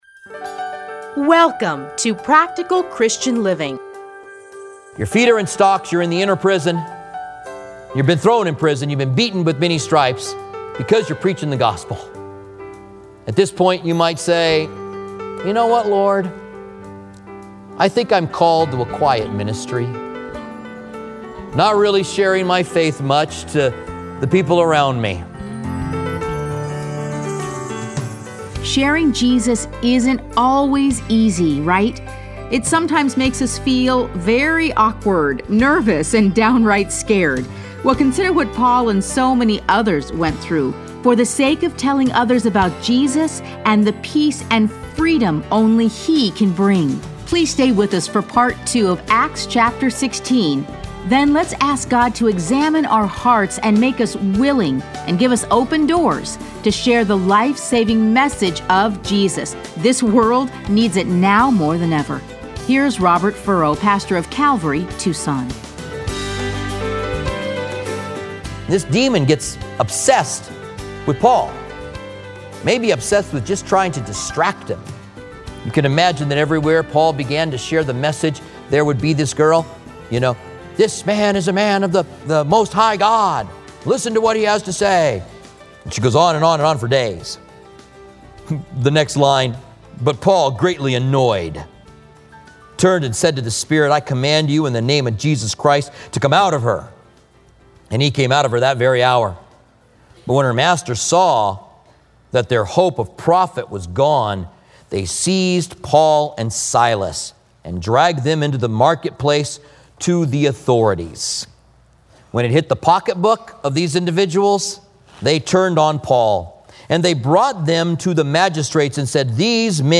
Listen to a teaching from Acts 16.